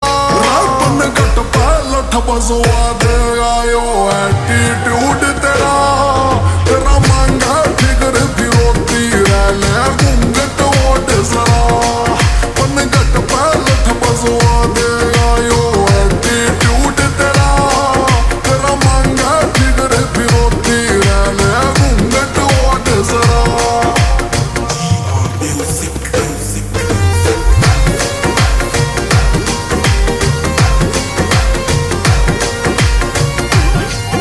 Haryanvi Songs
( Slowed + Reverb)